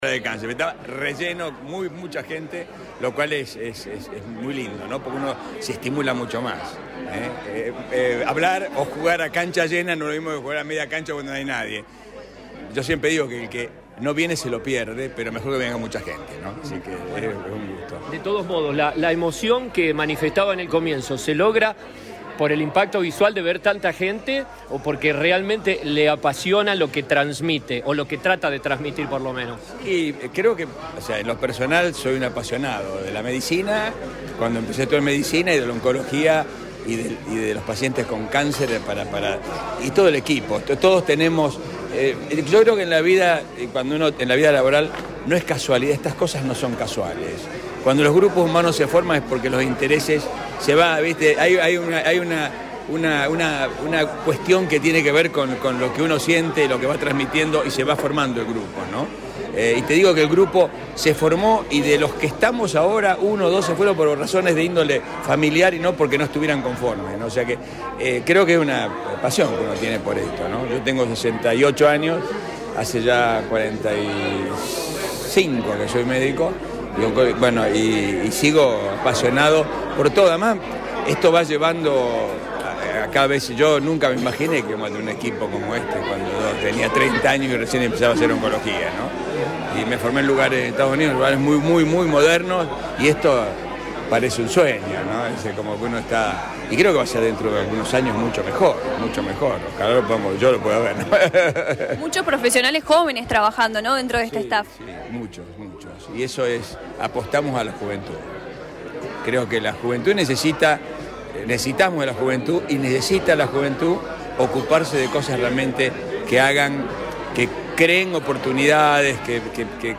En el Teatrillo Municipal se realizó esta charla informativa junto a los profesionales médicos de este Centro Oncológico que pertenece a Atilra.
Los médicos disertaron ante un auditorio lleno de público que escuchó atentamente sobre qué es el cáncer, cuáles son los factores predisponentes, métodos de prevención. Tratamientos y las ventajas del Thomo Therapy.